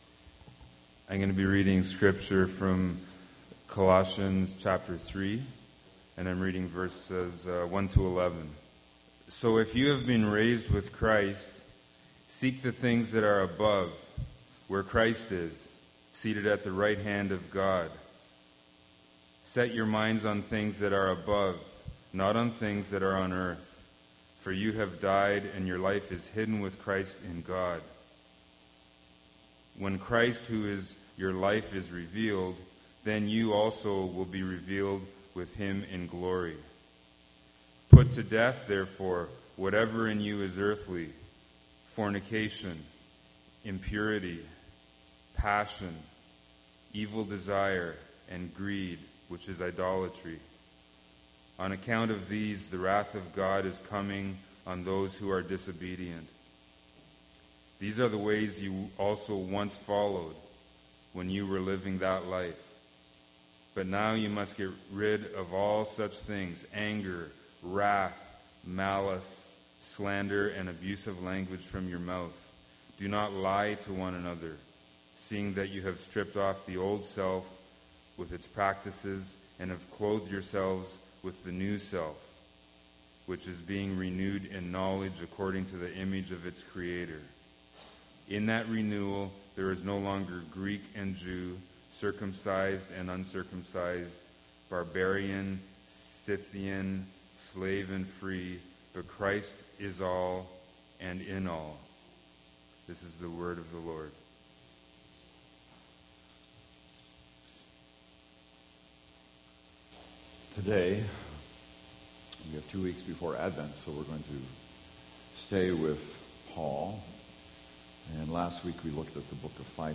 sermon_nov15.mp3